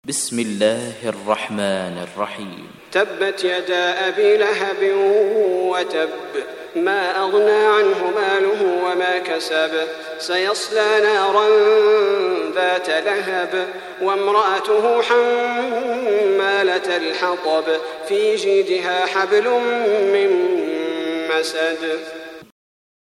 Surat Al Masad Download mp3 Salah Al Budair Riwayat Hafs dari Asim, Download Quran dan mendengarkan mp3 tautan langsung penuh